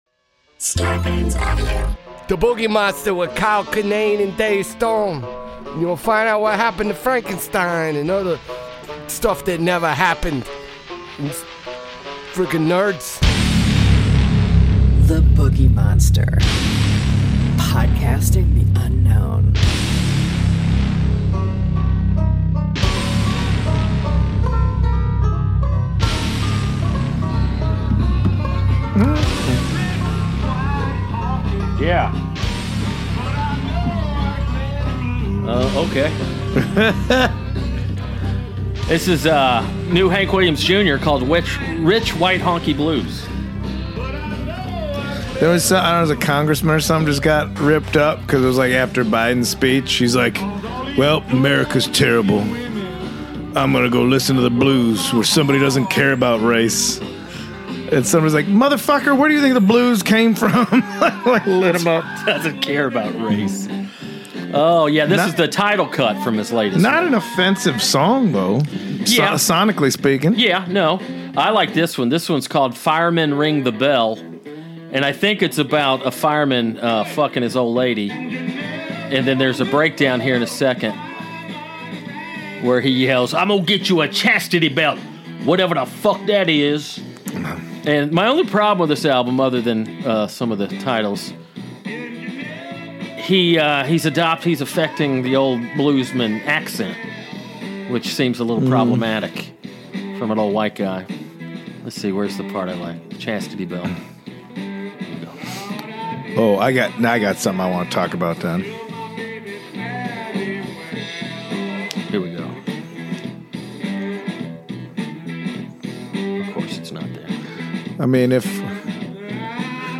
for a rare in-person episode